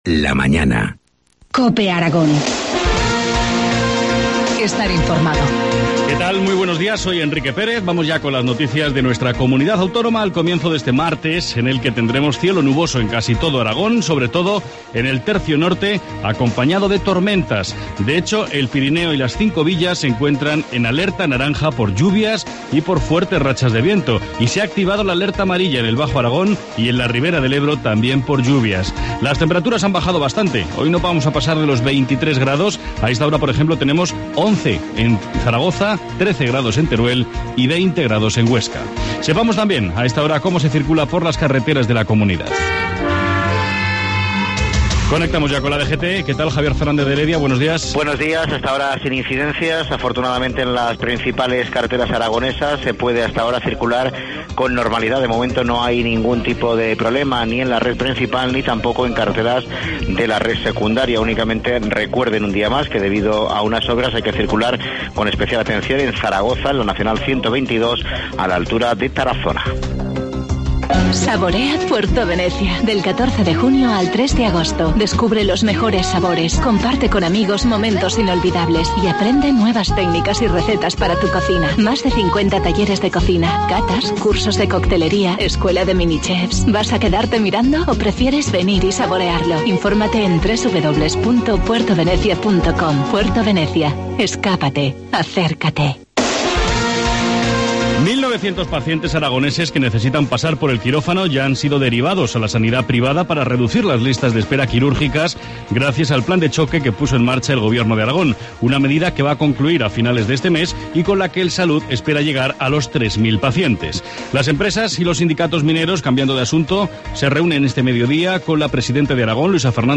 Informativo matinal, 18 junio, 7,25 horas